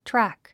発音
trǽk　トラァック